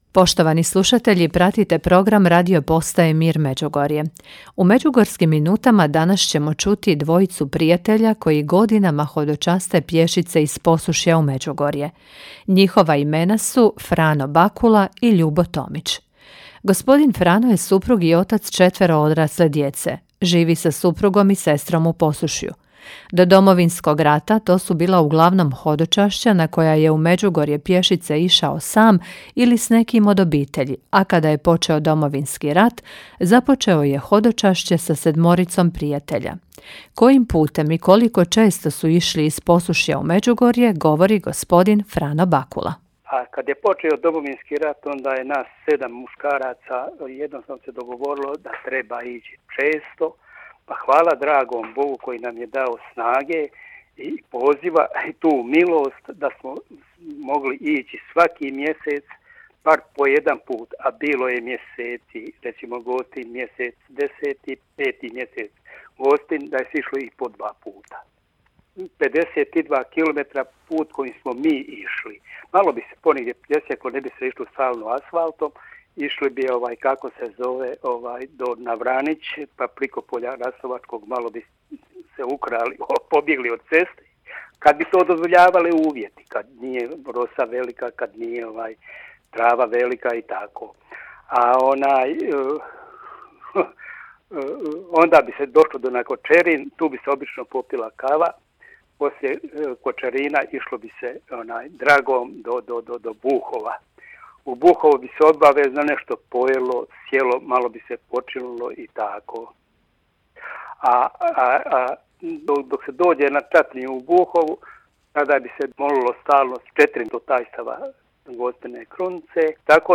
U Međugorskim minutama ugostili smo dvojicu prijatelja koji godinama hodočaste pješice iz Posušja u Međugorje.